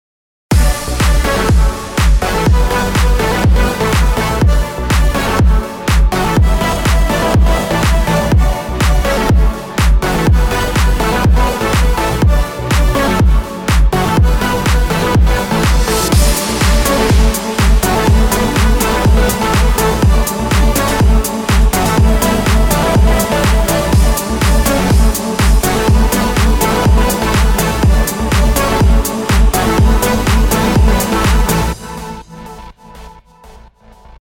נייס טראק.mp3 בסגנון פיוצ’ר באונס אני לא יודע עם אני עונה על ההגדרה אבל תשמעו